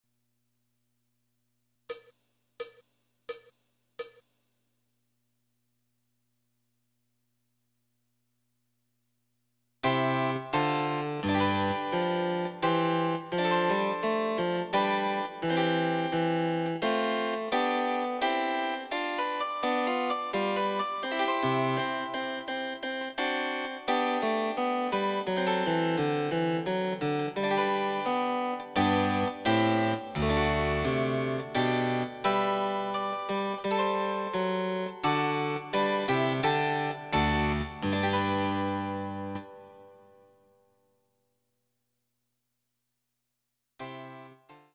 その他の伴奏
Electoric Harpsichord